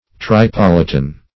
Search Result for " tripolitan" : The Collaborative International Dictionary of English v.0.48: Tripolitan \Tri*pol"i*tan\, a. Of or pertaining to Tripoli or its inhabitants; Tripoline.
tripolitan.mp3